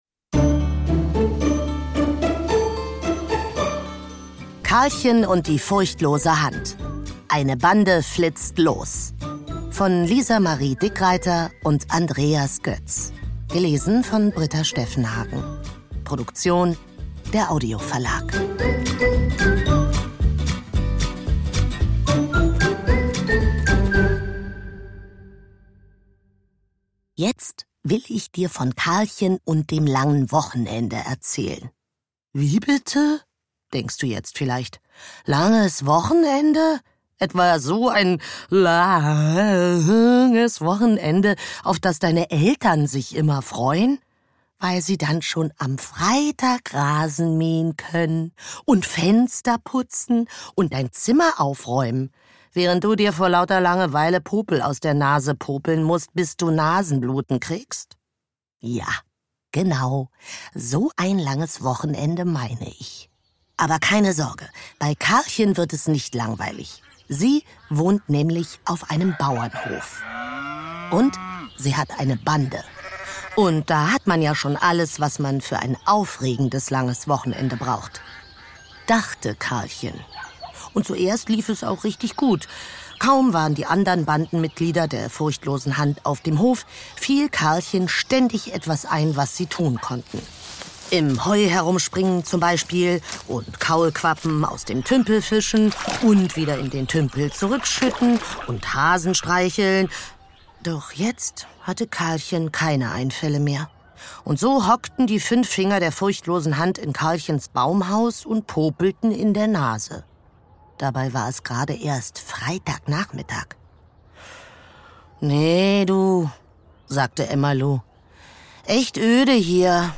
Ungekürzte szenische Lesung mit Musik
Schlagworte Abenteuer • Astrid Lindgren • Bande • Bauernhof • Berti und seine Brüder • Bullerbü • CD • Familie • Ferien • Freunde • Freundschaft • Geschichten für Jungen und Mädchen • Hörbuch • Humor • Kinder ab 4 • Michel aus Lönneberga • neuerscheinung 2024 • Opa • Streiche • Ungekürzt